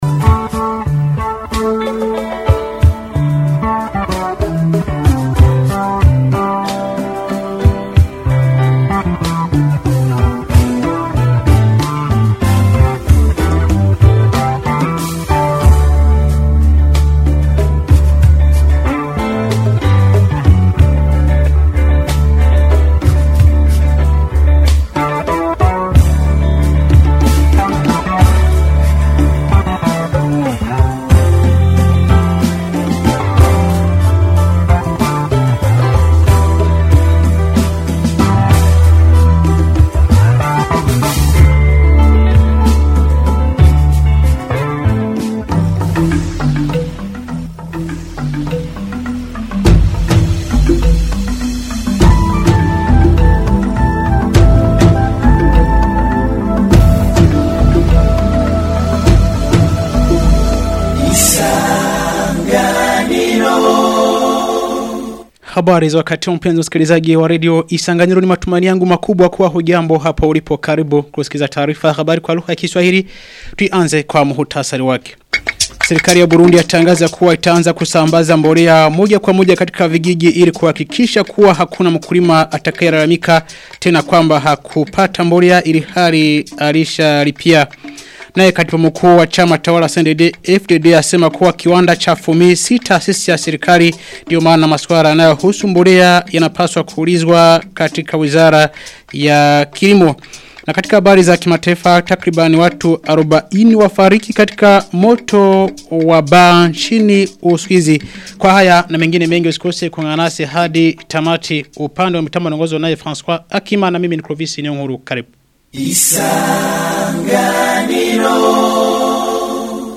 Taarifa ya habari ya tarehe 2 Januari 2026